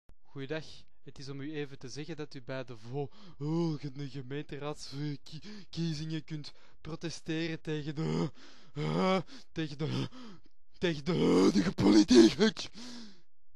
HATSHIE!!
niesbui.wma